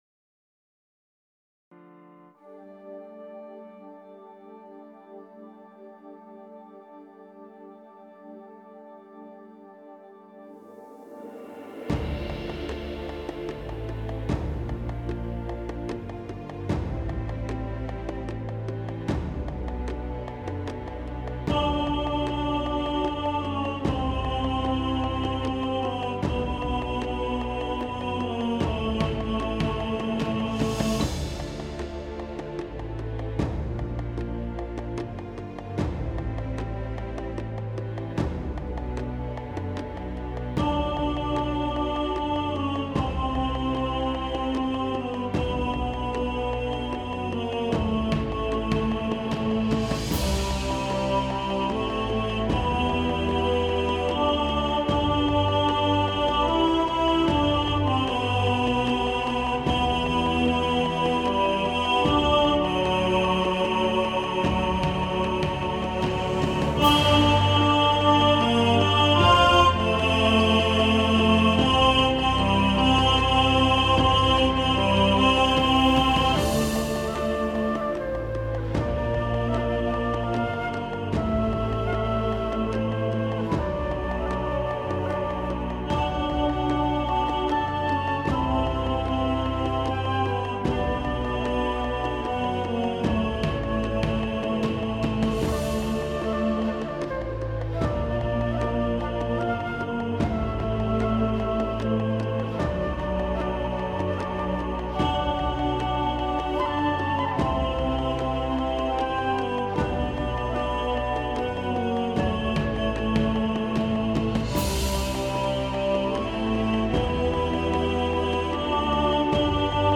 Adiemus – Tenor | Ipswich Hospital Community Choir